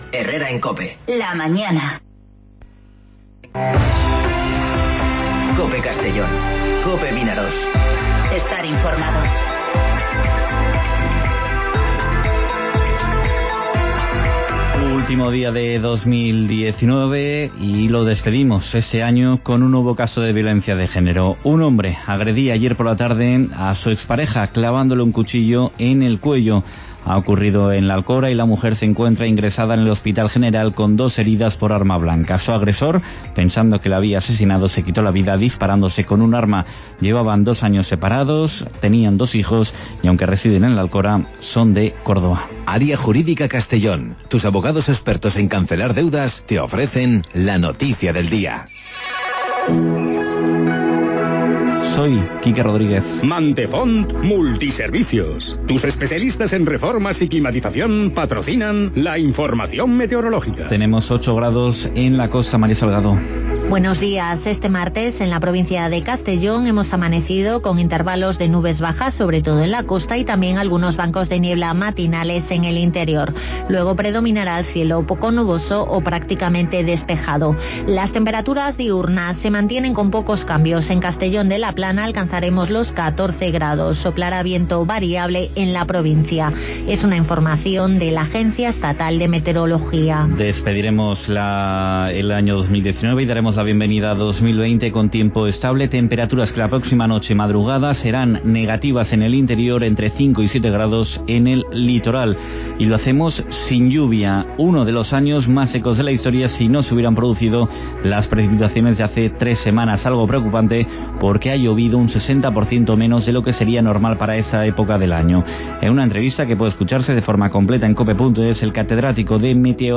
Informativo Herrera en COPE Castellón (31/12/2019)